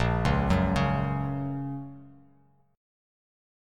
A#m#5 chord